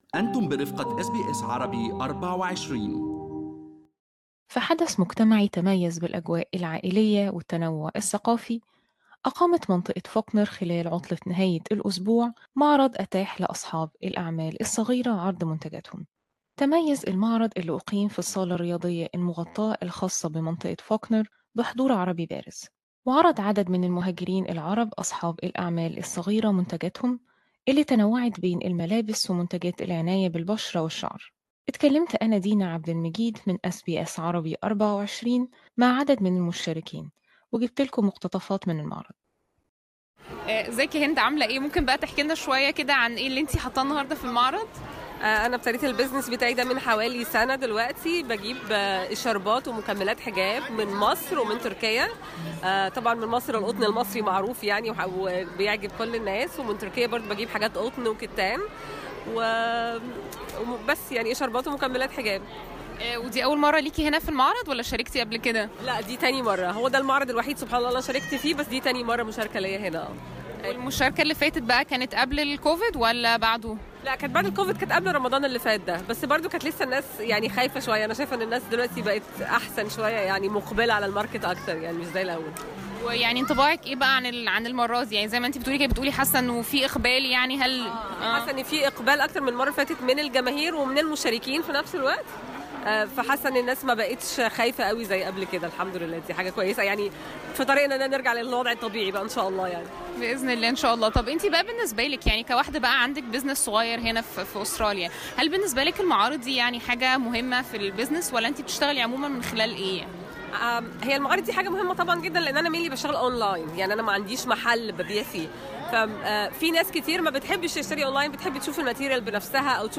تحدثت أس بي أس عربي مع عدد من المشاركين في معرض فوكنر الذي اتاح لأصحاب الأعمال الصغيرة عرض منتجاتهم والذي تميز بحضور عربي ملحوظ.